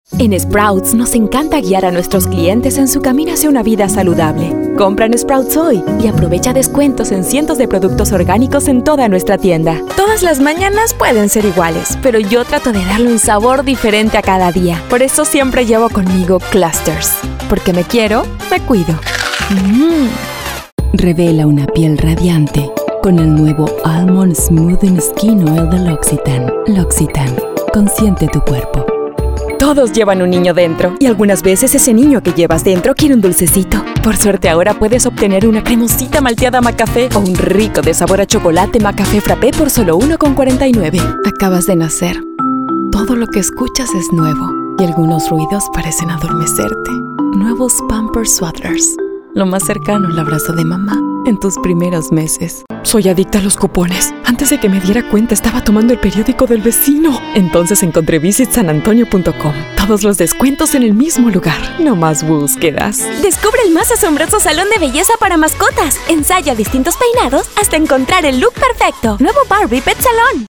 Spanish-speaking female voice actor
Neutral N. American, Puerto Rican, Bilingual